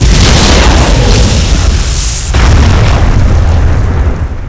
mortarhit.wav